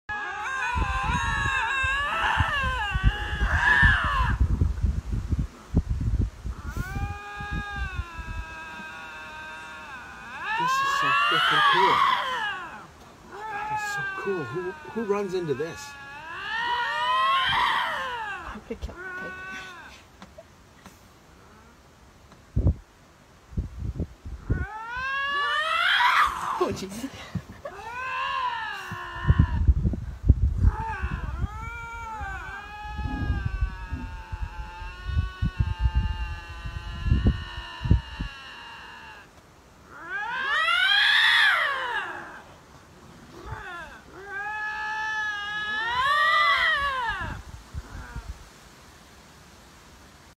2 Lynx screaming at each sound effects free download